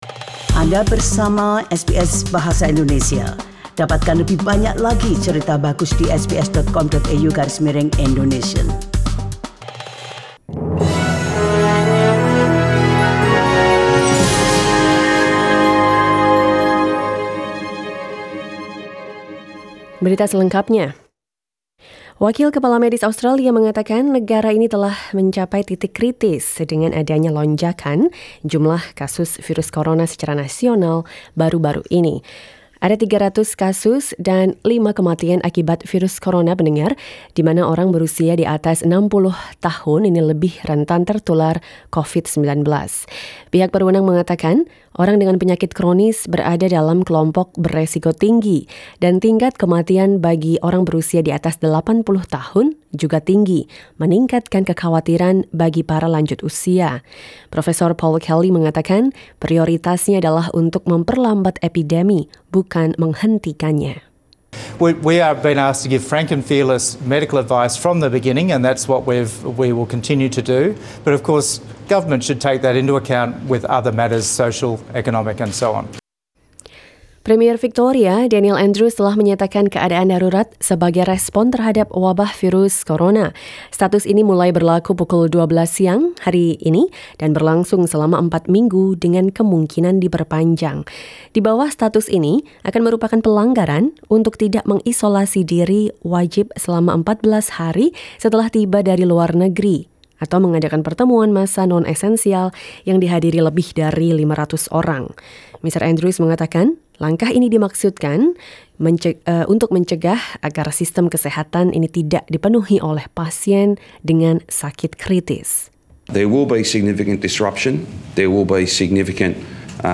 SBS Radio news in Indonesian 16 March 2020